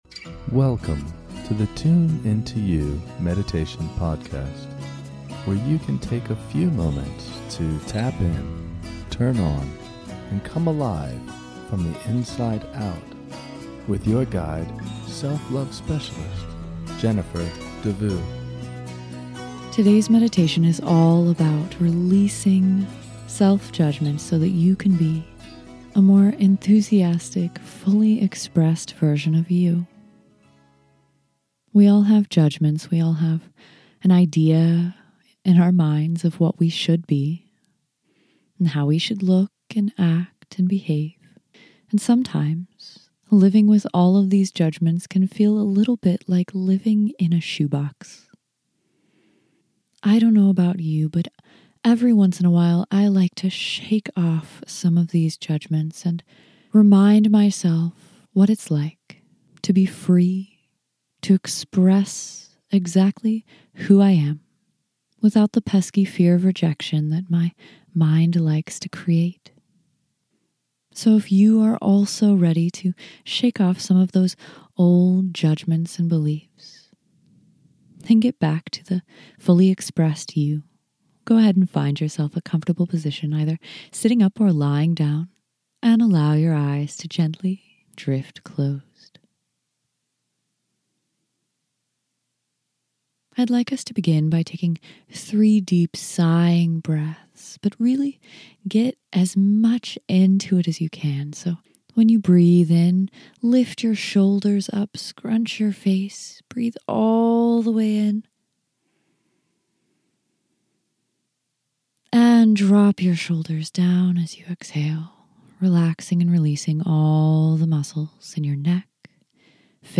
In this short guided meditation, you can shake off some of the self judgments that pile up in our minds. Shake off the self judgment so that you can live as your fully expressed self.